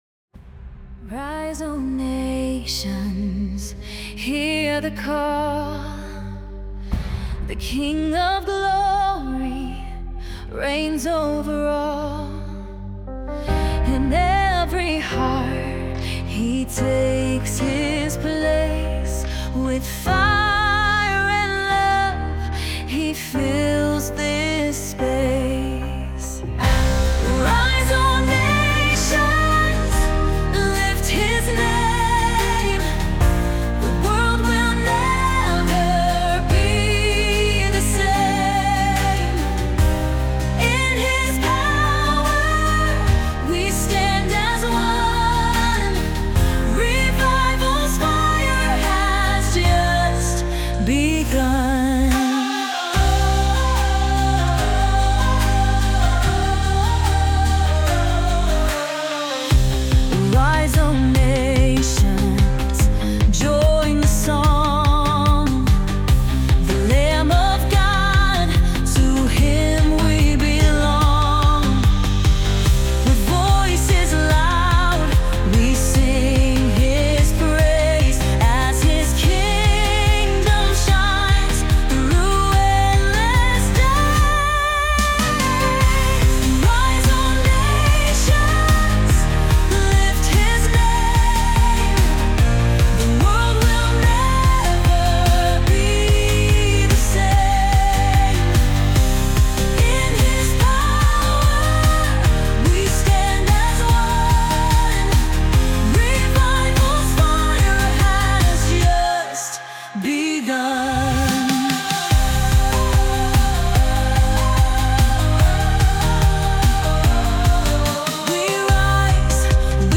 anthem
With bold lyrics and an uplifting melody